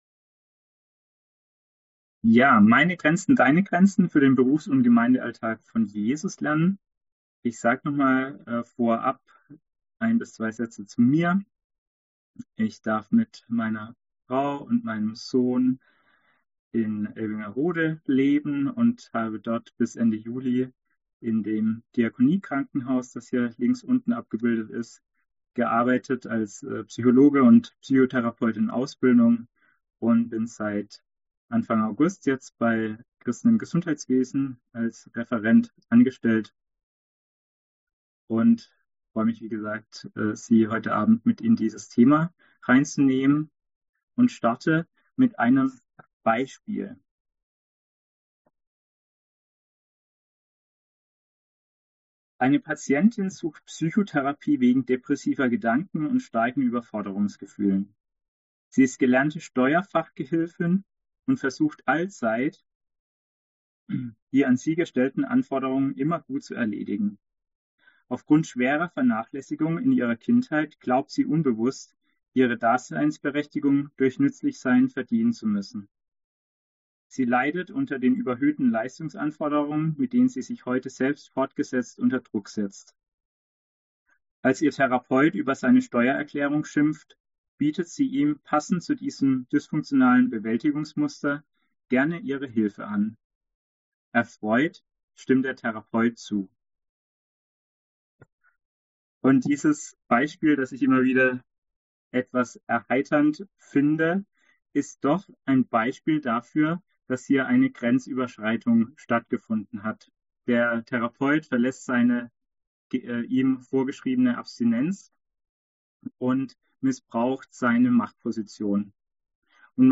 Digitales Kurzseminar